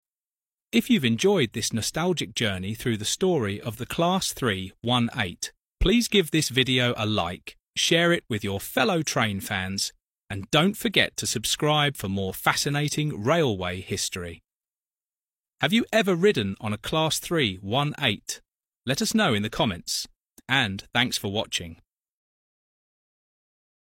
Class III I VIII_ A Nostalgic Train Sound Effects Free Download